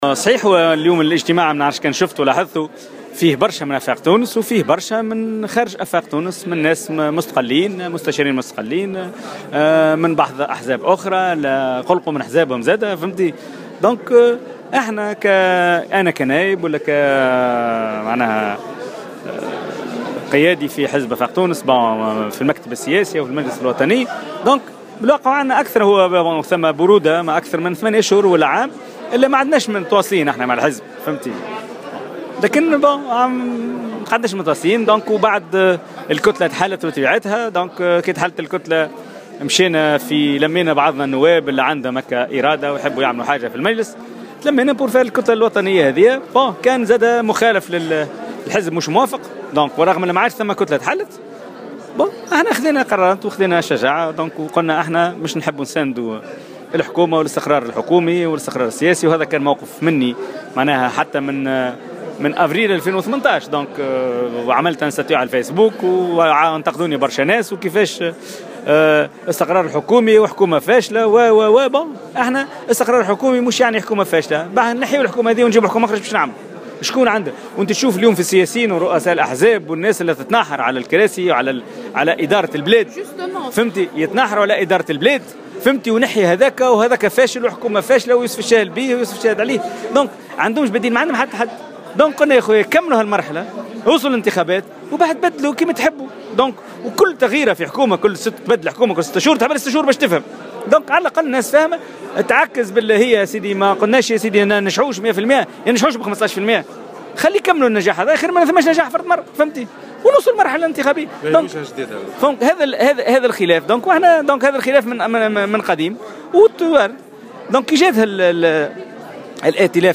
وأعرب في تصريح لموفد "الجوهرة أف أم" عن أمله في المشاركة في مشروع سياسي جديد وأن يكون من بين المؤسسين. وجاءت تصريحاته على هامش اجتماع نظمته مساء اليوم في سوسة قيادات آفاق تونس لاعلان الاستقالة بشكل رسمي والالتحاق بالمشروع السياسي الجديد المحسوب على رئيس الحكومة يوسف الشاهد.